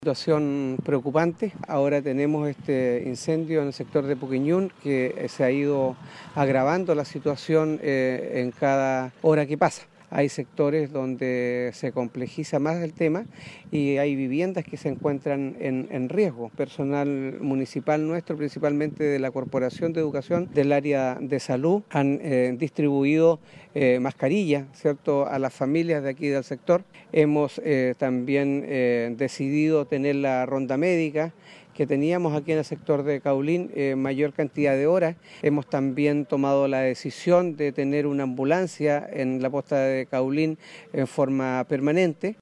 El edil de Ancud manifestó que numerosas personas de las localidades se han visto muy afectadas por la presencia de humo.